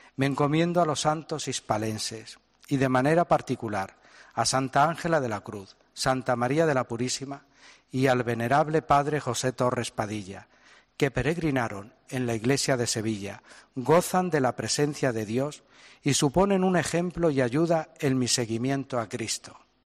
El nuevo obispo auxiliar de Sevilla, Teodoro León, quien ejerce actualmente como vicario general y moderador de la Curia Diocesana ha dirigido un saludo de agradecimiento durante la rueda de prensa celebrada la mañana de este sábado en el Palacio Arzobispal, donde se hizo público su nombramiento de parte del Santo Padre.